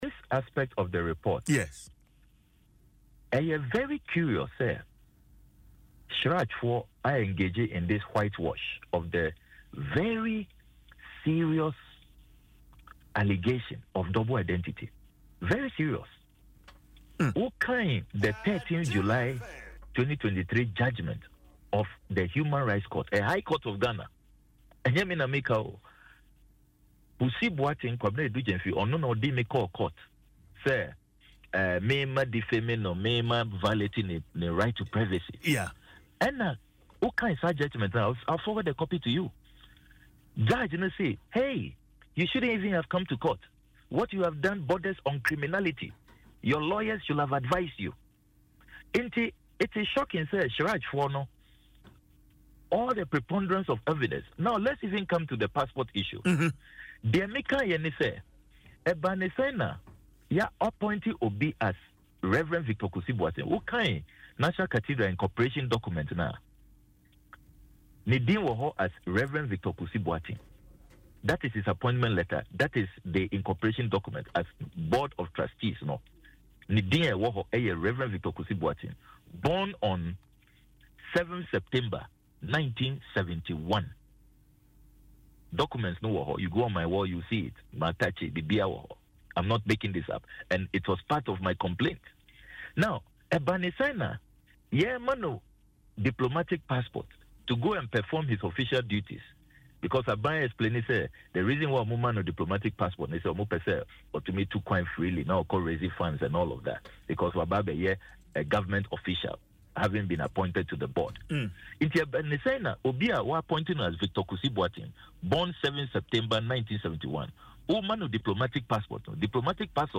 In an interview on Adom FM Dwaso Nsem, he argued that CHRAJ failed to fully examine all the evidence presented, relying solely on passport documentation.